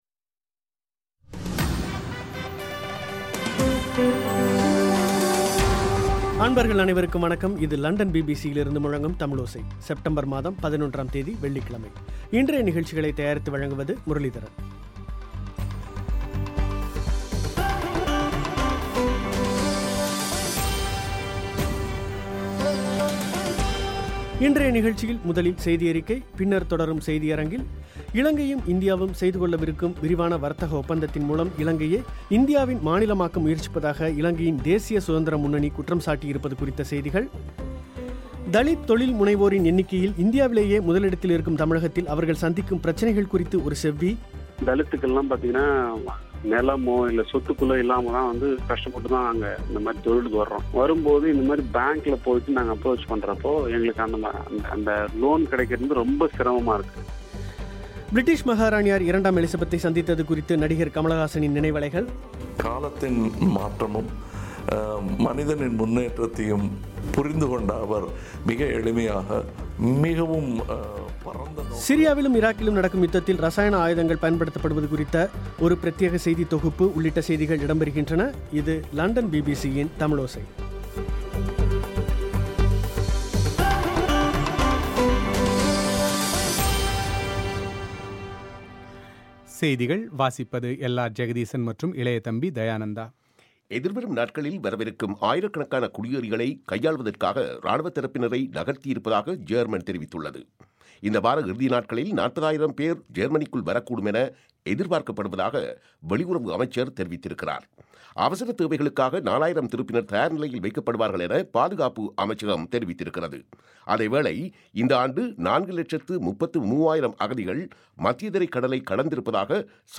இலங்கைக்கும் இந்தியாவுக்கும் இடையிலான விரிவான வர்த்தக ஒப்பந்தத்தின் மூலம் இலங்கையை இந்தியாவின் மாநிலமாக்க முயற்சிப்பதாக இலங்கையின் தேசிய சுதந்திர முன்னணி குற்றம் சாட்டியிருப்பது, இந்தியாவில் 2006ல் நடந்த ரயில் குண்டுவெடிப்பு சம்பவத்தில் 12 பேர் குற்றவாளிகள் என தீர்ப்பளிக்கப்பட்டிருப்பது, தலித் தொழில் முனைவோரின் எண்ணிக்கையில் இந்தியாவிலேயே முதலிடத்தில் இருக்கும் தமிழகத்தில் அவர்கள் சந்திக்கும் பிரச்சனைகள் குறித்து ஒரு செவ்வி, பிரிட்டிஷ் மாகாராணியார் இரண்டாம் எலிசபெத்தைச் சந்தித்தது குறித்து நடிகர் கமல்ஹாசனின் நினைவலைகள் உள்ளிட்ட செய்திகள் இடம்பெறுகின்றன.